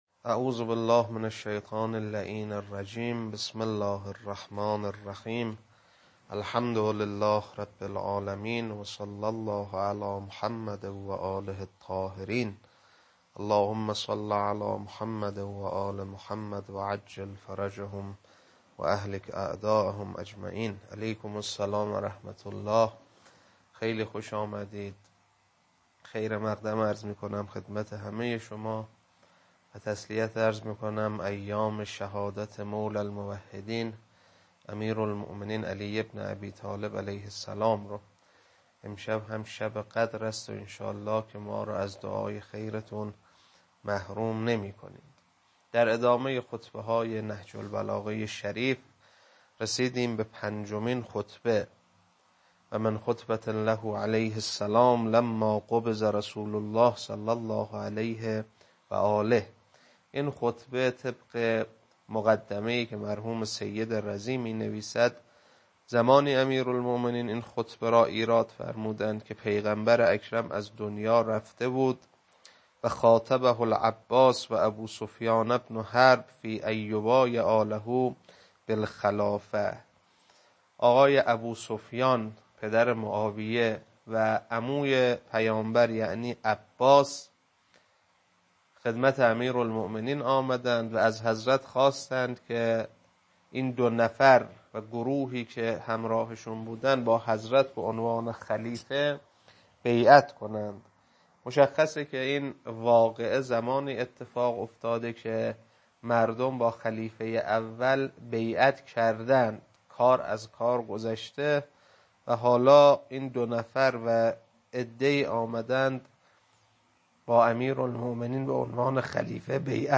خطبه 5.mp3
خطبه-5.mp3